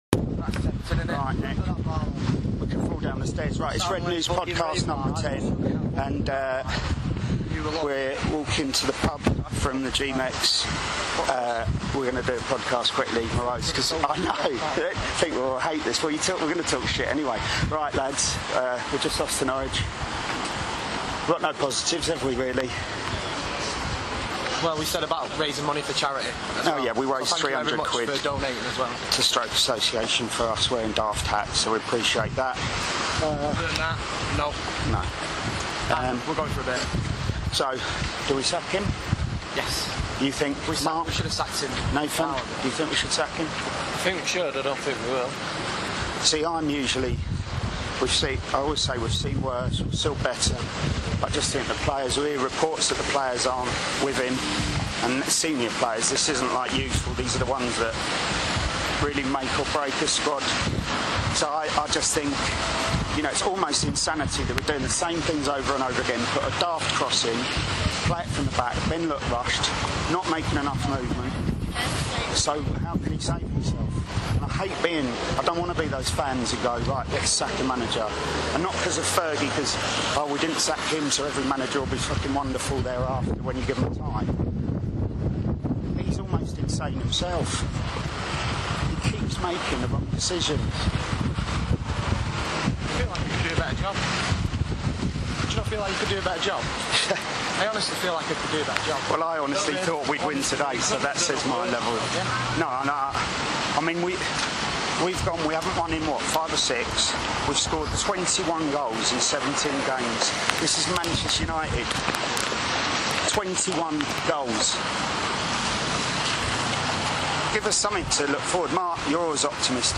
The independent, satirical Manchester United supporters' fanzine - for adults only, contains expletives - continues with waffle and bad sound. The sellers discuss in the shortest recording ever their anger at the 2-1 defeat to Norwich and LvG's future.